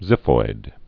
(zĭfoid)